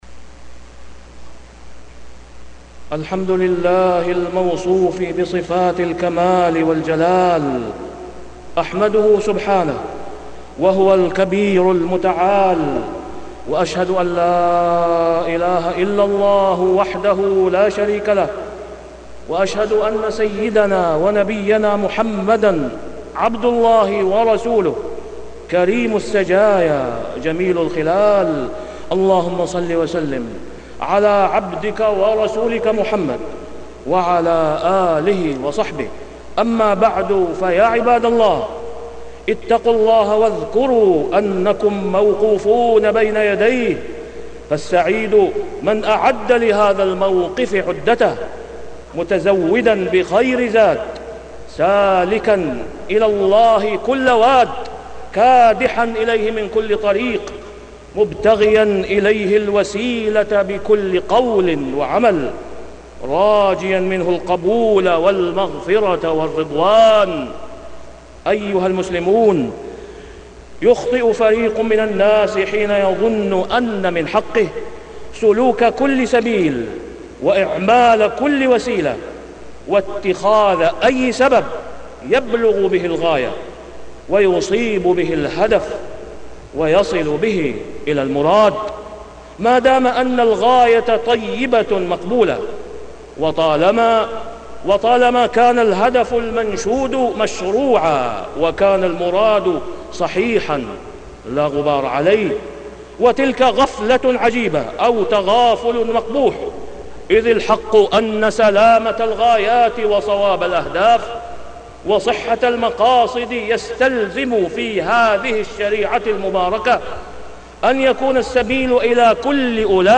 تاريخ النشر ٧ ربيع الثاني ١٤٢٧ هـ المكان: المسجد الحرام الشيخ: فضيلة الشيخ د. أسامة بن عبدالله خياط فضيلة الشيخ د. أسامة بن عبدالله خياط شرف المقاصد والوسائل